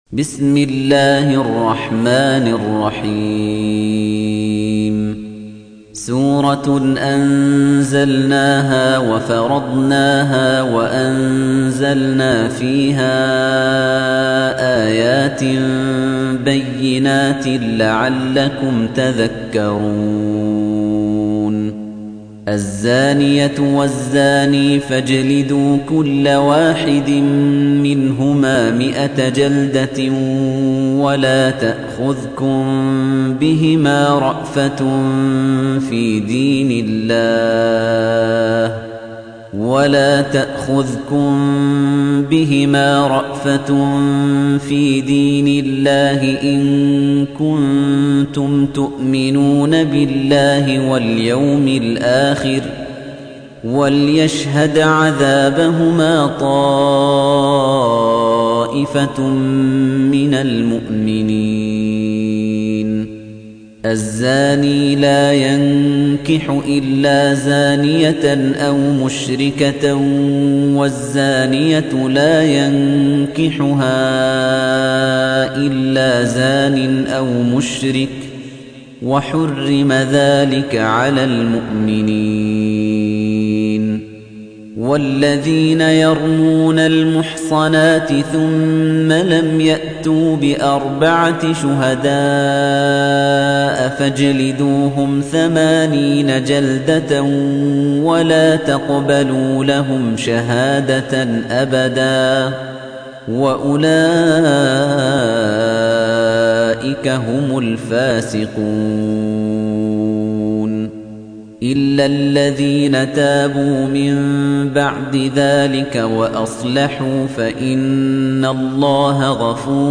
تحميل : 24. سورة النور / القارئ خليفة الطنيجي / القرآن الكريم / موقع يا حسين